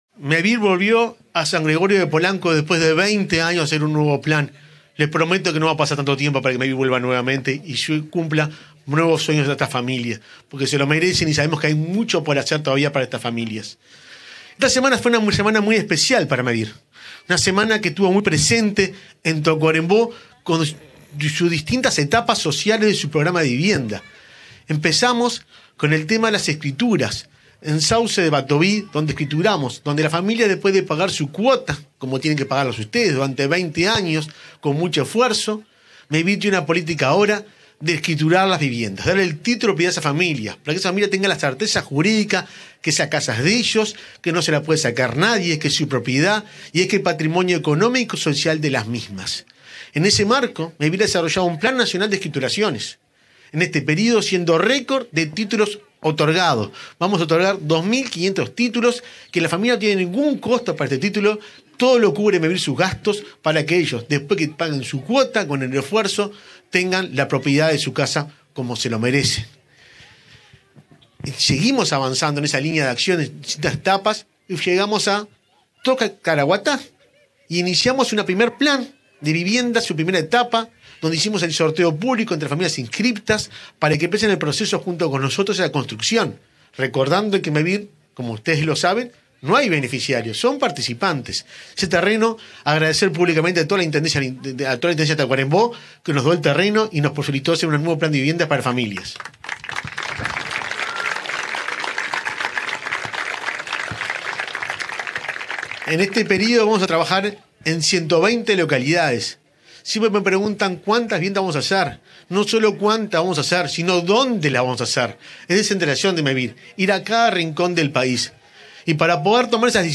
Palabras del presidente de Mevir, Juan Pablo Delgado
En el marco de la entrega de 47 viviendas sustentables de Mevir construidas en madera, en el departamento de Tacuarembó, este 26 de julio, se expresó